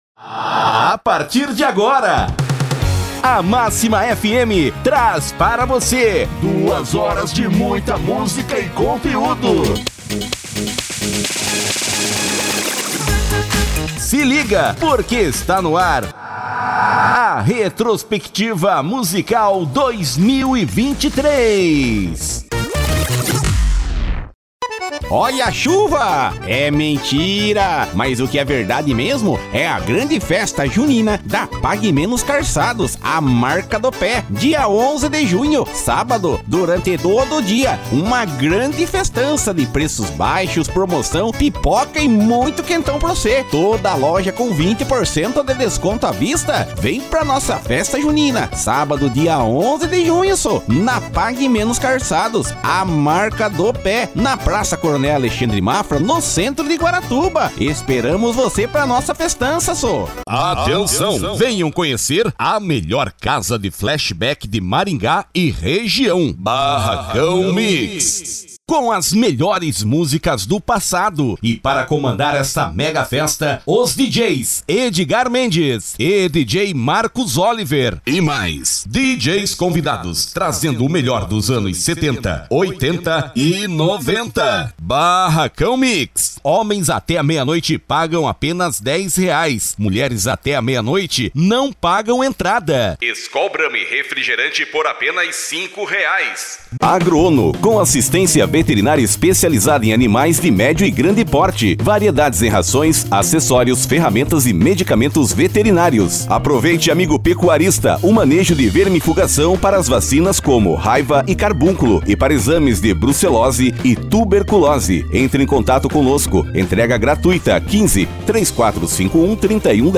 Estilo(s): PadrãoImpacto Animada Varejo
Demonstrativo principal: Formatos: Spot Comercial Vinhetas Chamada de Festa VT Comercial Aberturas VSL URA Espera Telefônica Áudio Visual Post em Áudio Gravação Política Estilo(s): Padrão Impacto Animada Varejo